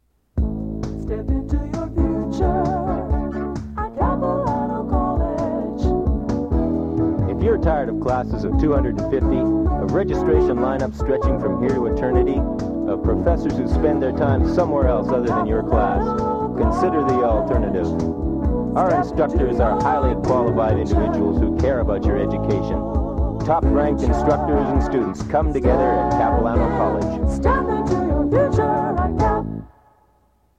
Audio non-musical
Capilano College Promotional Audio/Radio Jingle.
audio cassette